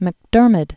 (mək-dûrmĭd), Hugh Pen name of Christopher Murray Grieve. 1892-1978.